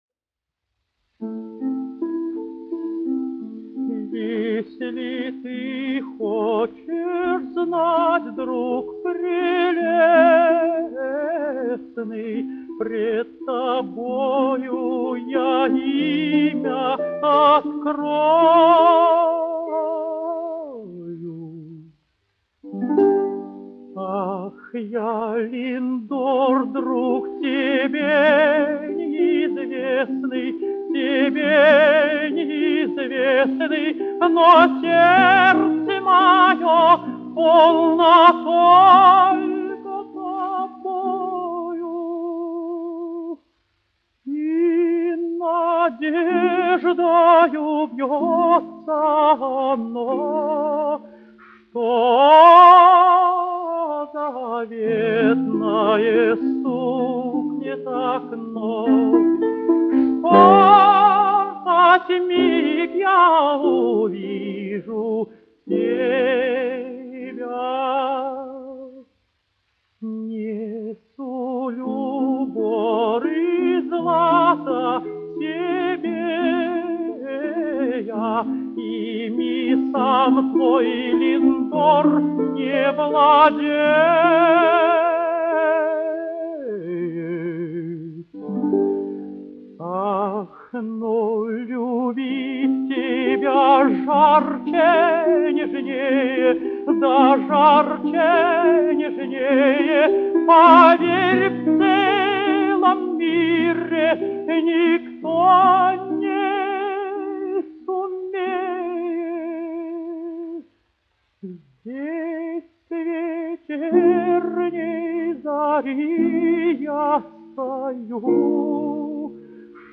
Виноградов, Георгий Павлович, 1908-1980, dziedātājs
1 skpl. : analogs, 78 apgr/min, mono ; 25 cm
Operas--Fragmenti
Latvijas vēsturiskie šellaka skaņuplašu ieraksti (Kolekcija)